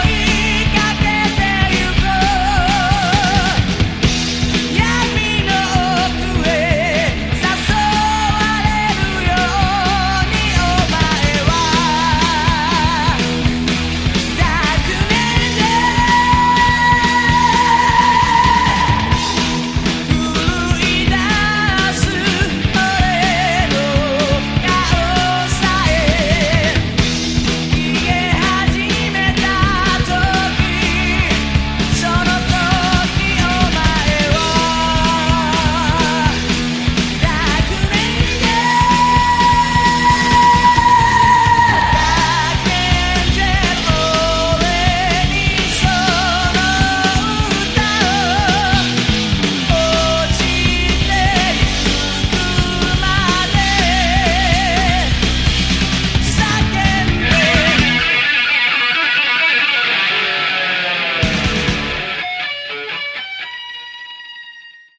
Category: Hard Rock
bass
guitar
vocals
drums